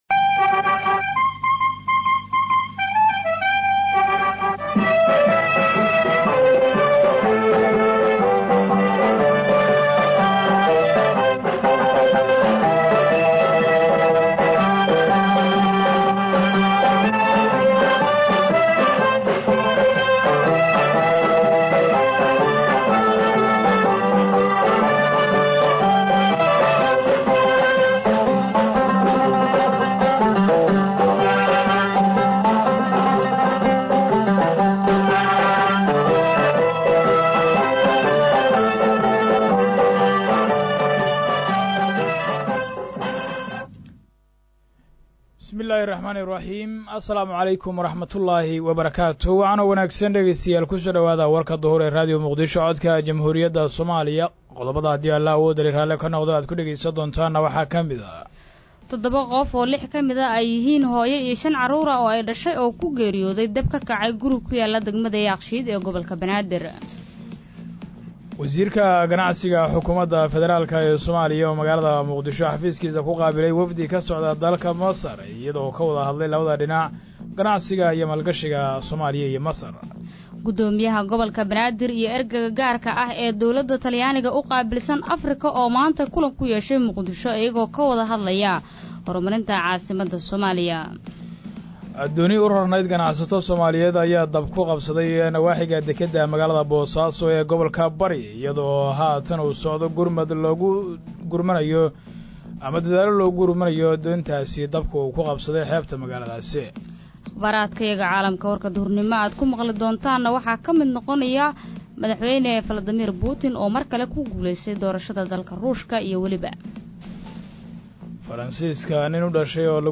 Dhageyso: Warka Duhur ee Radio Muqdisho